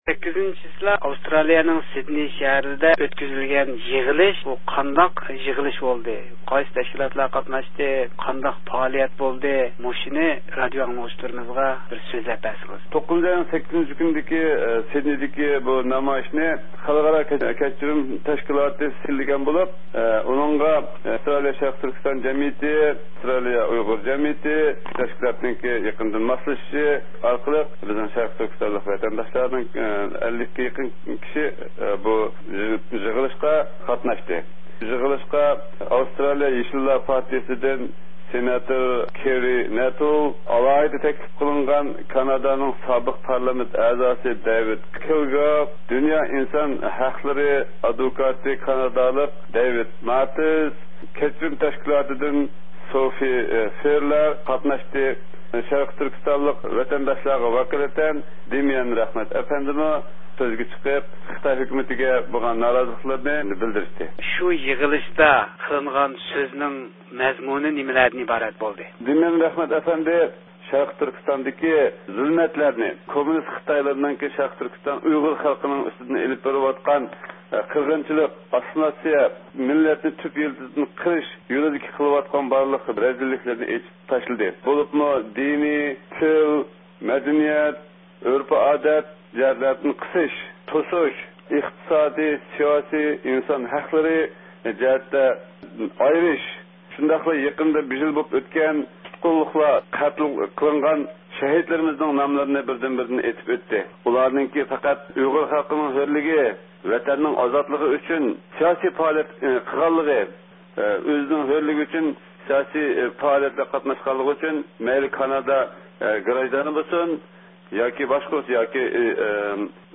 تېلېفون سۆھبىتىنىڭ تەپسىلاتىنى ئاڭلايسىلەر